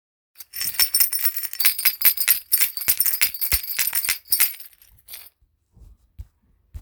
ドゴンストローがらがら　小
手のひらサイズが使いやすい、水草を編み上げたガラガラです。カゴの中に、金属片・響きのよい小石が入っていて、ベル系の音がします。底は丸くカットしたひょうたんが編み込まれています、優しく心地よい音、自然の音が和みます。
素材： 水草・小石・金属